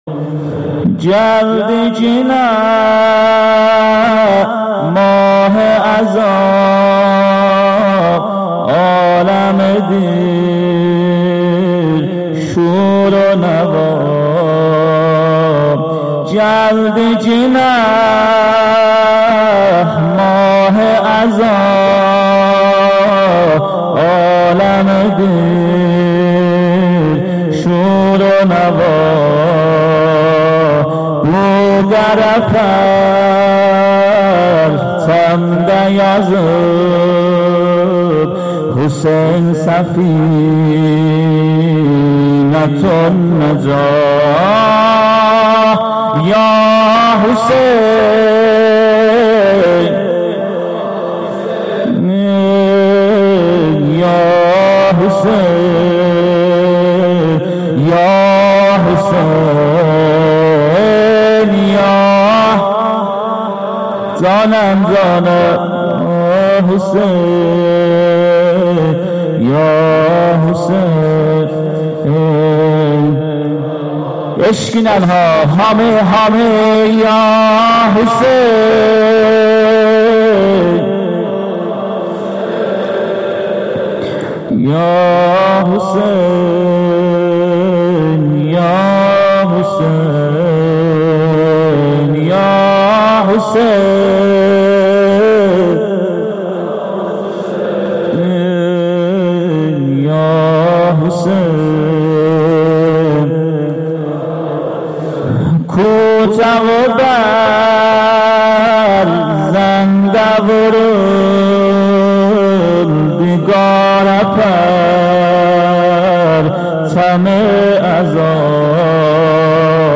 هیأت محبان اهل بیت علیهم السلام چایپاره